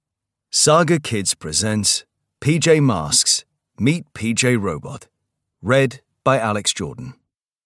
Audio knihaPJ Masks - Meet PJ Robot (EN)
Ukázka z knihy